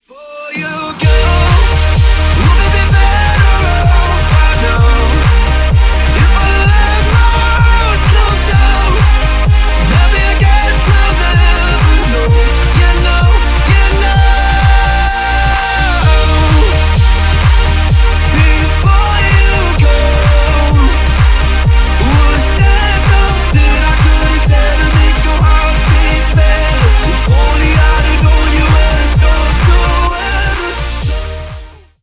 • Качество: 320 kbps, Stereo
Ремикс
Поп Музыка